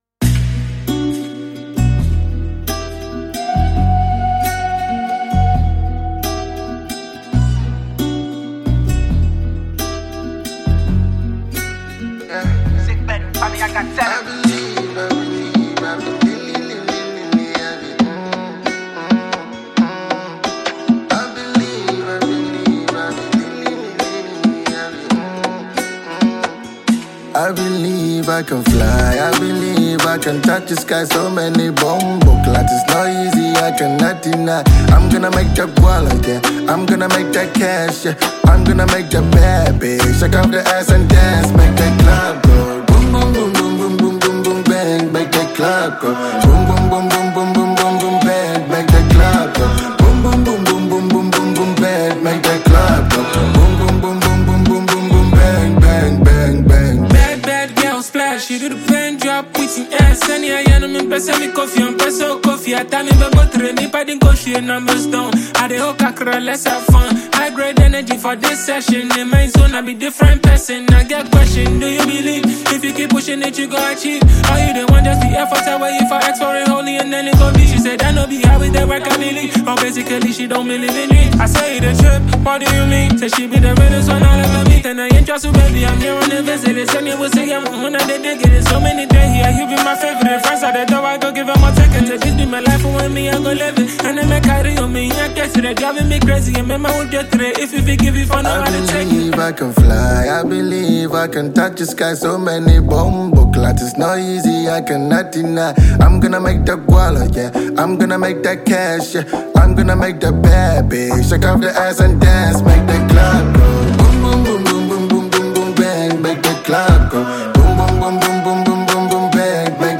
Asakaa rapper